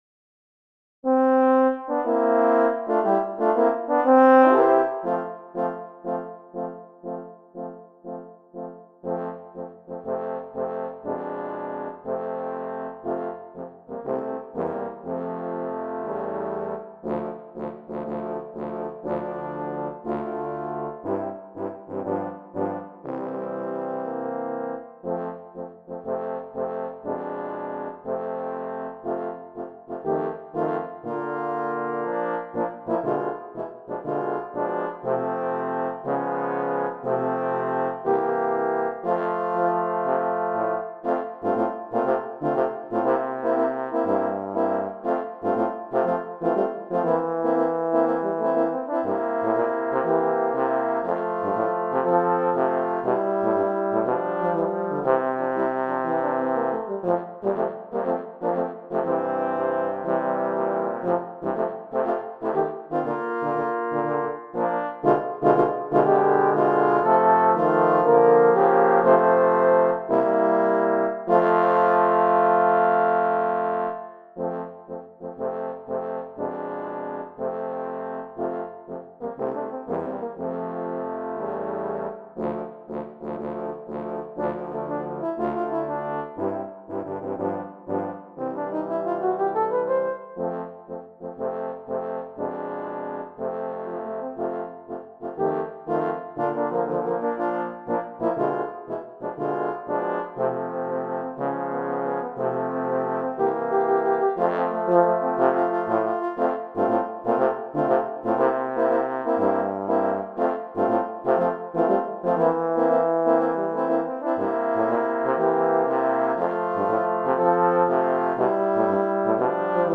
I have arranged it for horn quartet.
If ever a hymn sounded like a march, this is it.